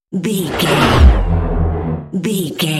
Sci fi big vehicle whoosh
Sound Effects
futuristic
intense
whoosh
vehicle